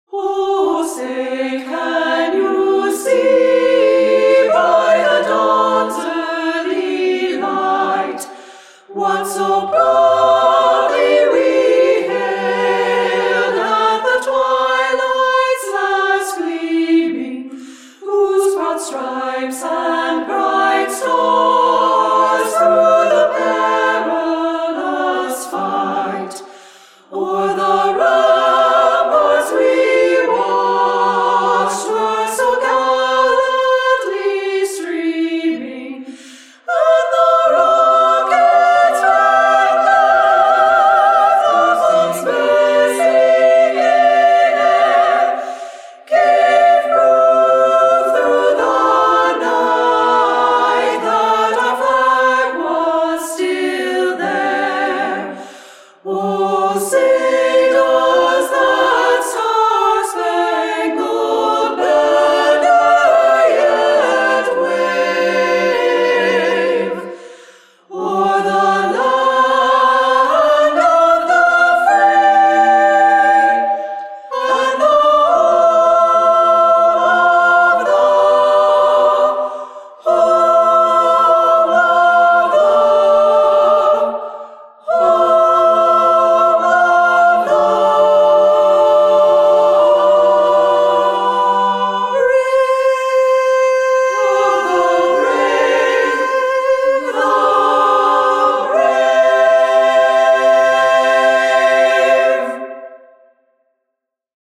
for SSAA choir a cappella